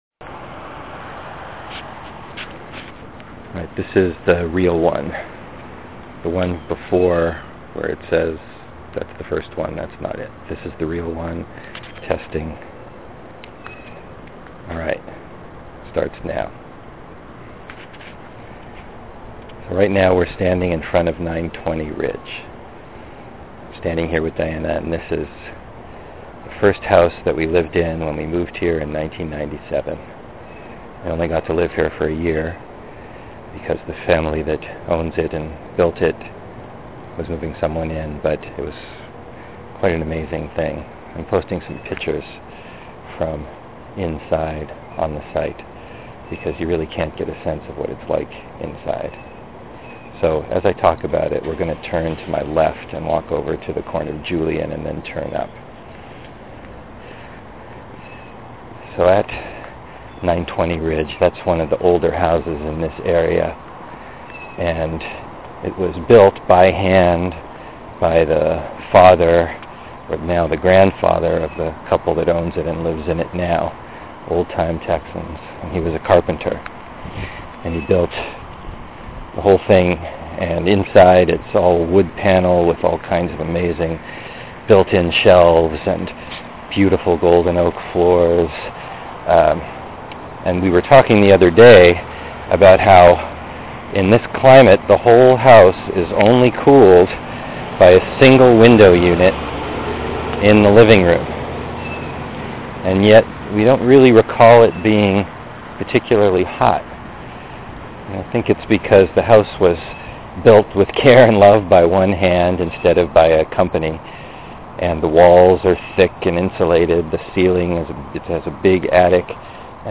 In this Hear Our Houston audio tour and contribution to Unexpected City